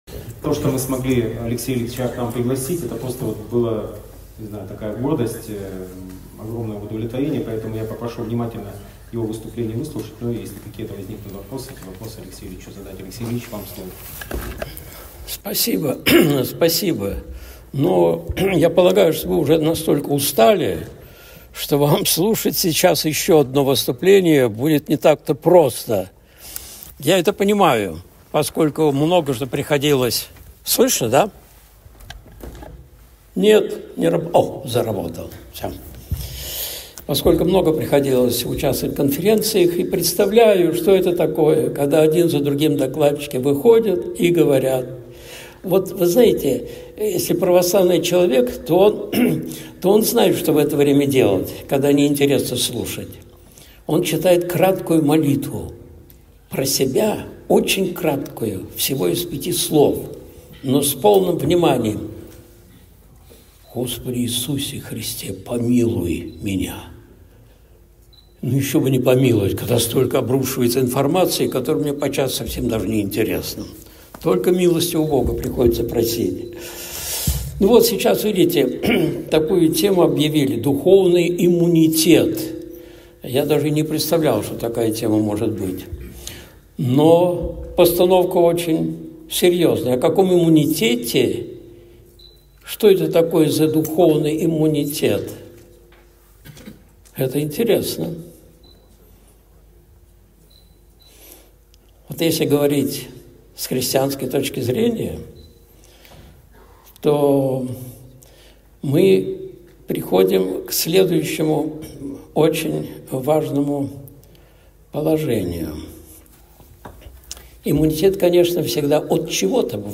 Необходим духовный иммунитет (Выступление в Росгвардии, 30.08.2024)